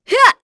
Glenwys-Vox_Attack2.wav